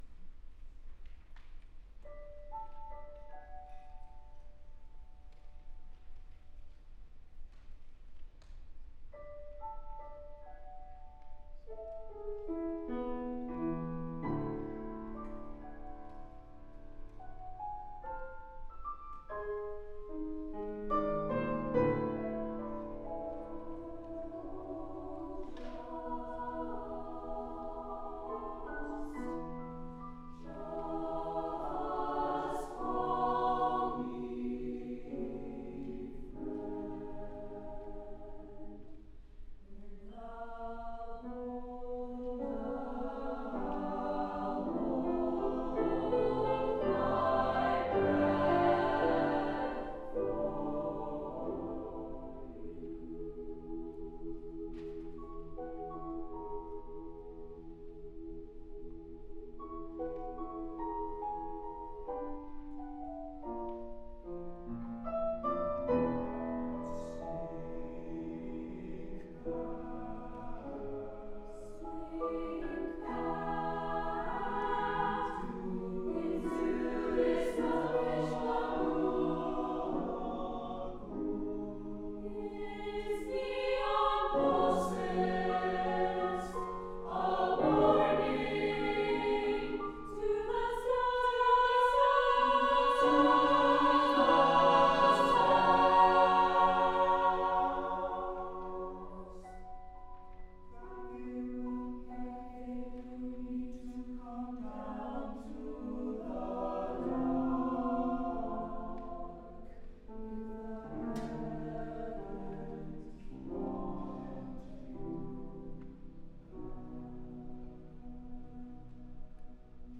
piano
Beatch-Dream-of-Me-April-2025-Claremont-Concert-Choir.mp3